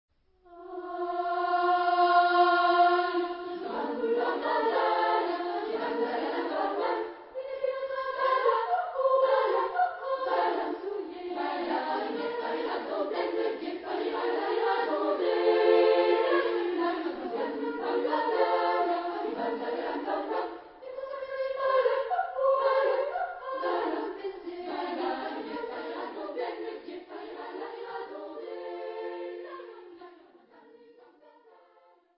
Género/Estilo/Forma: Popular ; Danza ; Rondó ; Profano
Carácter de la pieza : rápido
Tipo de formación coral: SAA  (3 voces Coro femenino )
Tonalidad : si bemol mayor